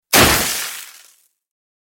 Ground-smash-sounde-effect.mp3